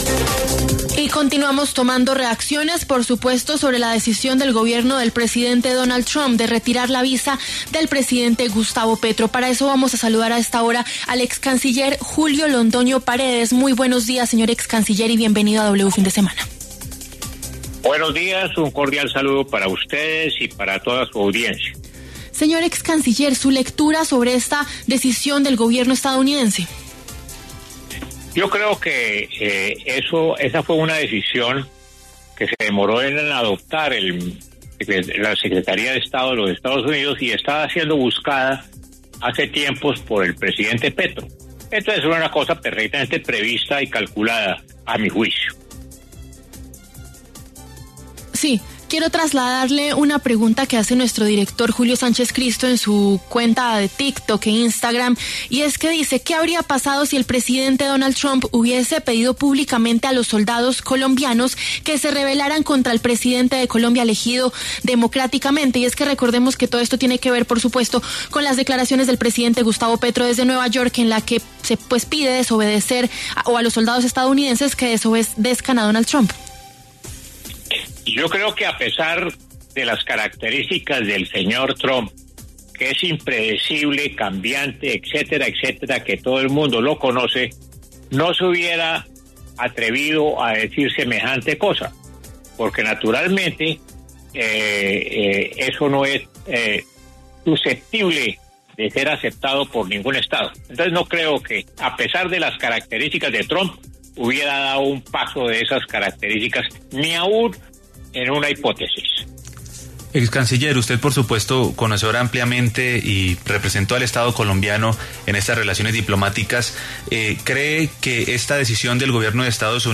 El excanciller Julio Londoño habló en W Fin de Semana sobre la decisión del Gobierno de Estados Unidos de retirarle la visa al presidente Gustavo Petro.